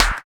71 SNARE.wav